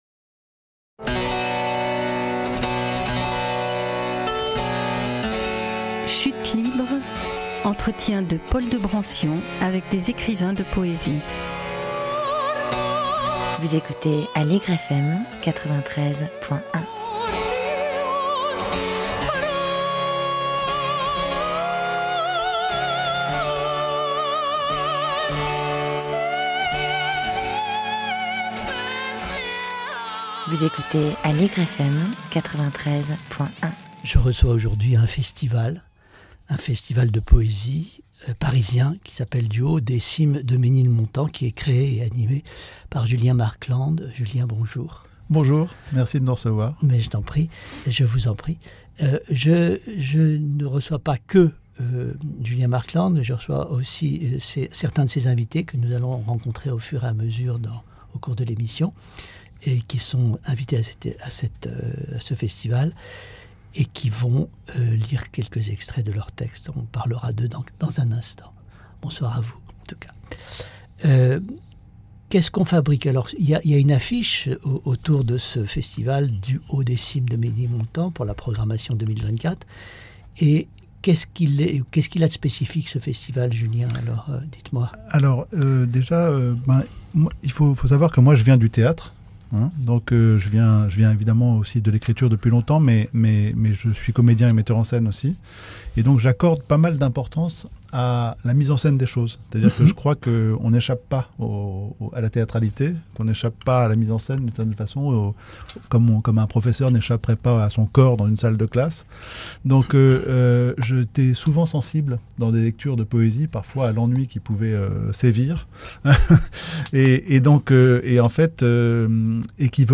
pour une émission spéciale lors du festival de poésie parisien.